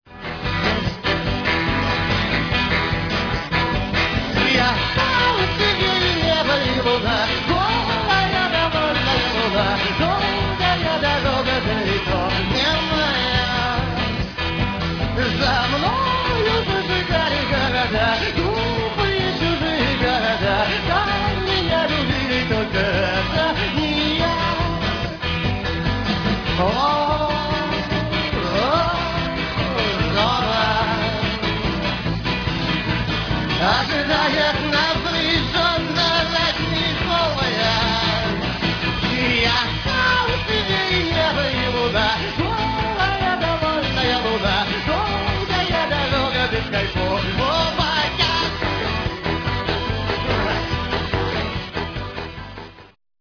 Концерт в Горбушке (1996)
фрагмент песни